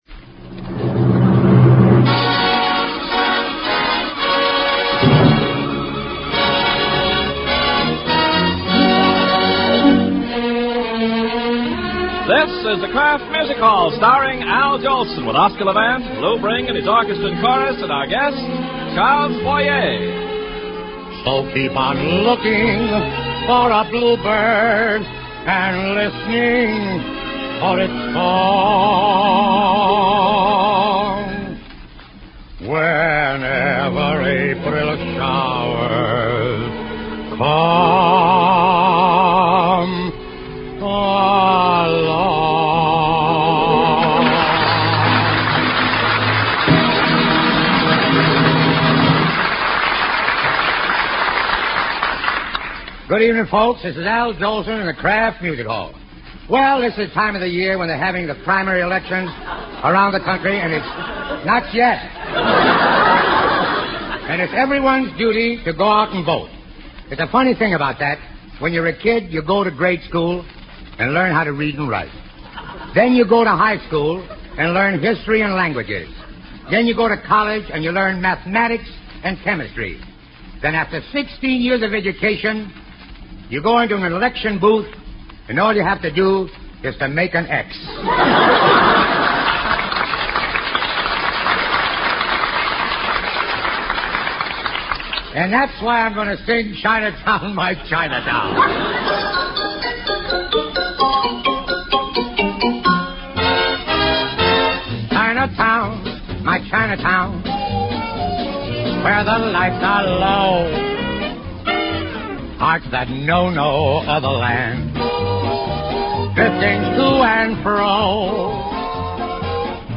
Guest: Charles Boyer (5/23/1943) Singer: Dale Evans